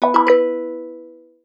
incomingchat.wav